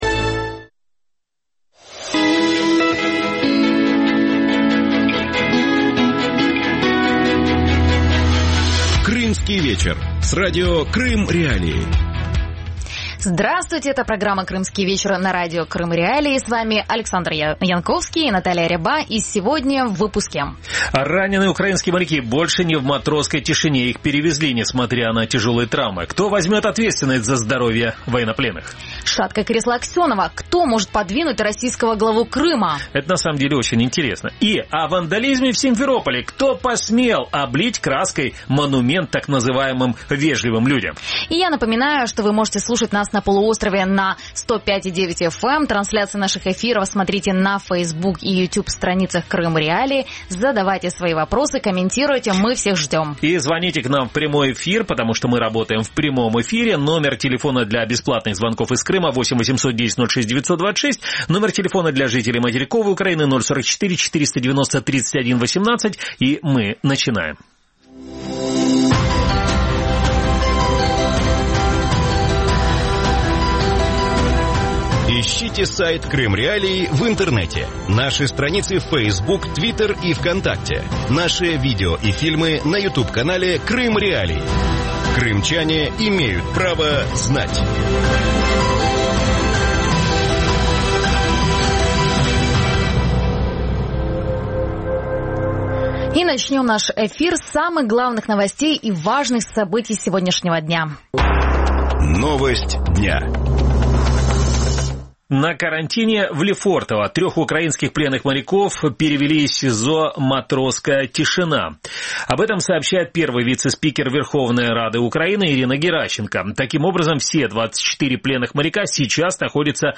Эти и другие актуальные темы в студии Радио Крым.Реалии в ток-шоу «Крымский вечер» обсуждают ведущие